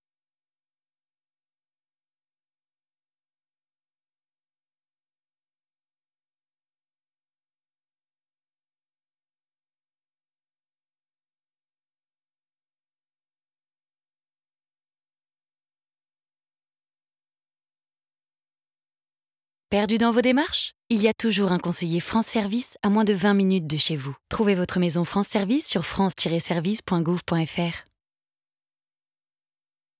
Rap Slam